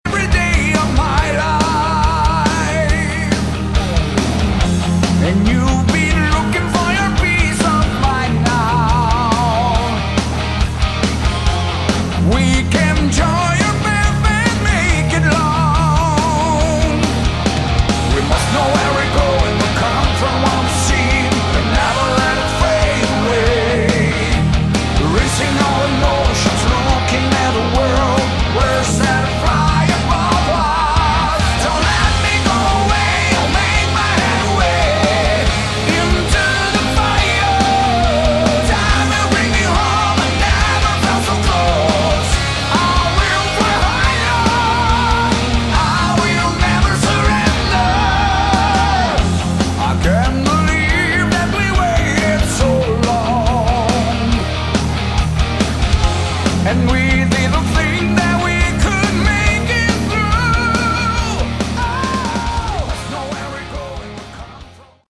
Category: Melodic Metal
vocals
guitars
bass, keyboards, backing vocals
drums